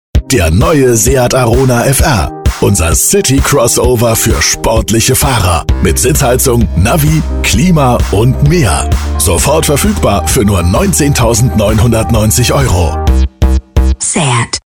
Seat TV ad
Middle Aged